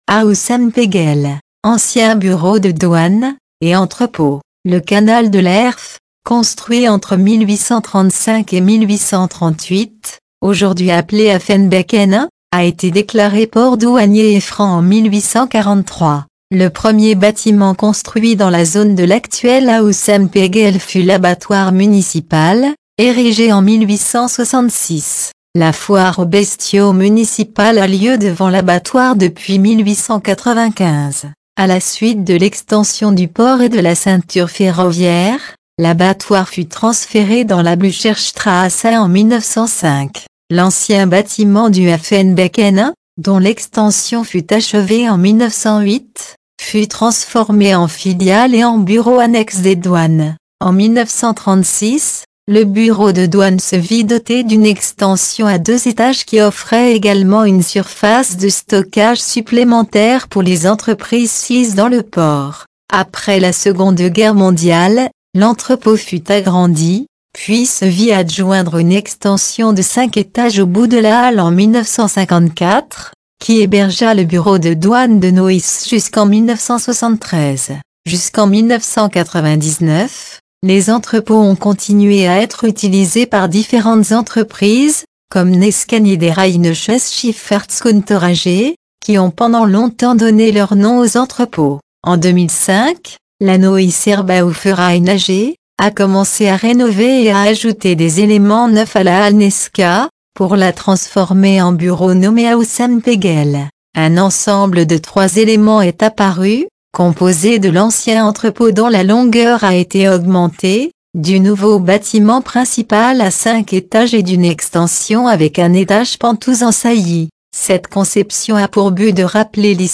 Audio Guide Français